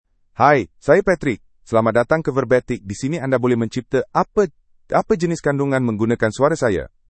MaleMalayalam (India)
PatrickMale Malayalam AI voice
Patrick is a male AI voice for Malayalam (India).
Voice sample
Listen to Patrick's male Malayalam voice.
Patrick delivers clear pronunciation with authentic India Malayalam intonation, making your content sound professionally produced.